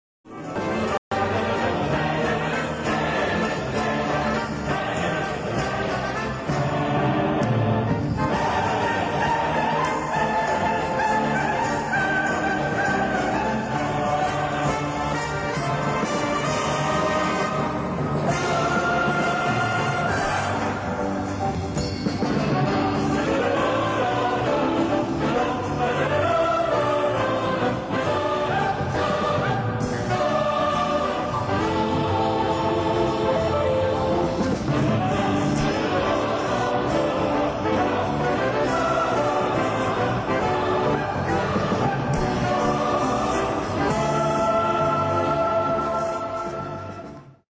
Für gemischten Chor, Big Band und Streicher
Besetzung: gemischter Chor, Orchester und Big Band
Internationale Funkausstellung Berlin, 1976